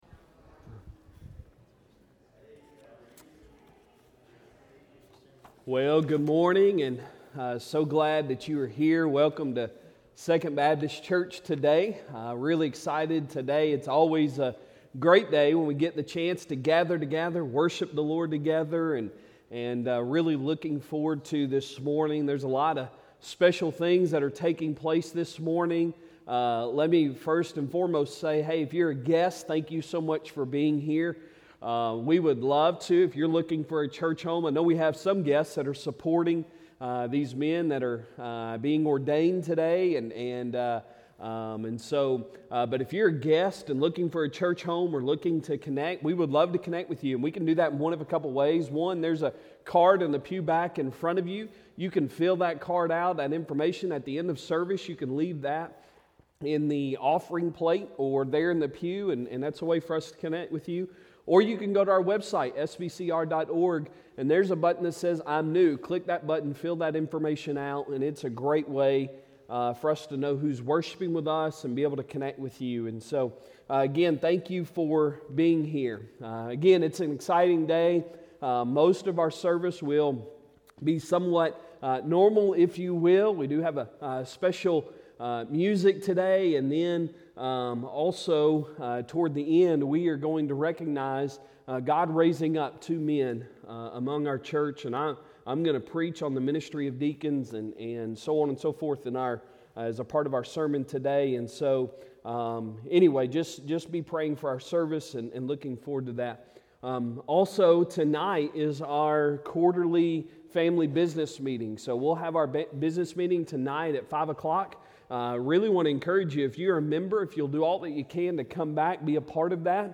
Sunday Sermon November 19, 2023